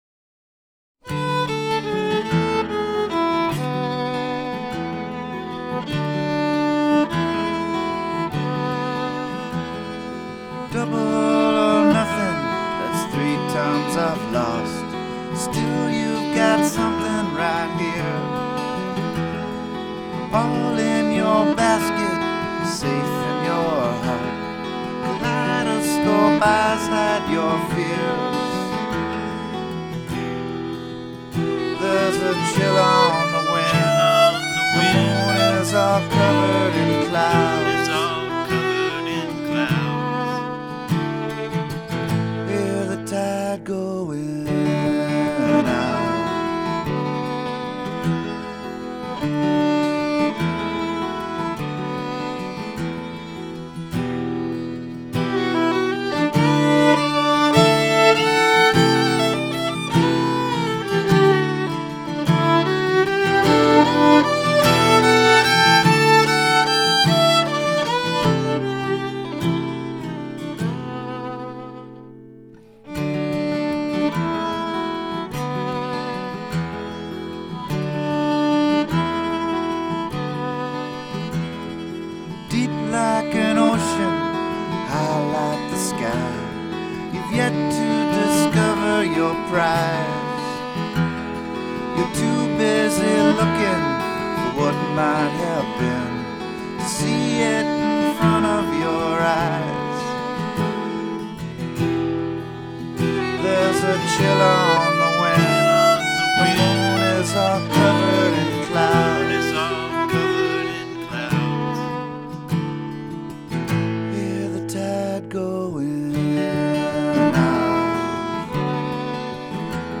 Guitar and vocals
Mandolin, fiddle, and vocals